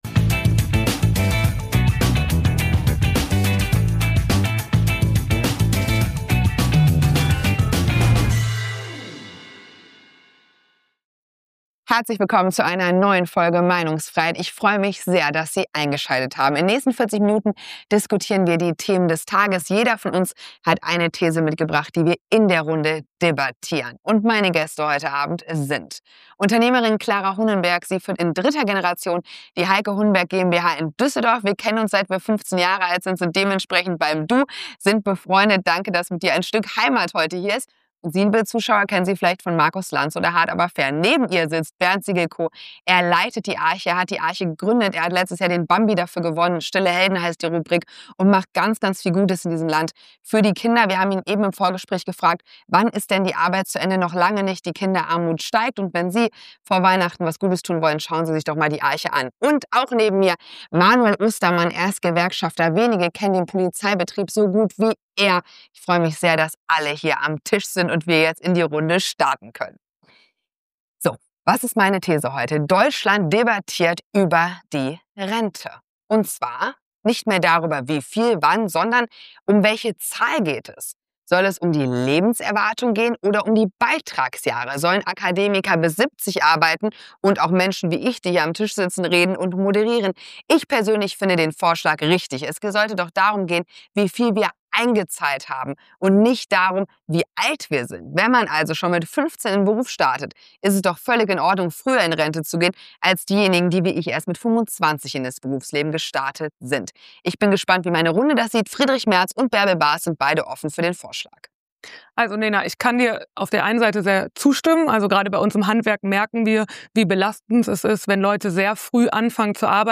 Das Konzept sieht vor, dass jeder Gast eine eigene These mit in die Sendung bringt und diese mit den anderen Gästen und der Moderation diskutiert.